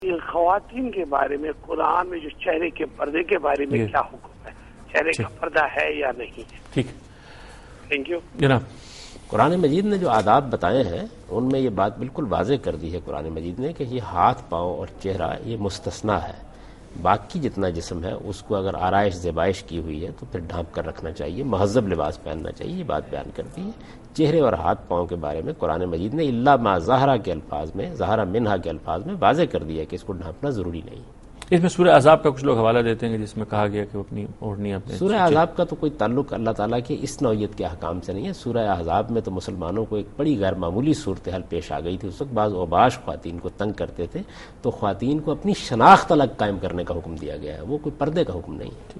Answer to a Question by Javed Ahmad Ghamidi during a talk show "Deen o Danish" on Duny News TV
دنیا نیوز کے پروگرام دین و دانش میں جاوید احمد غامدی ”چہرے کا پردہ “ سے متعلق ایک سوال کا جواب دے رہے ہیں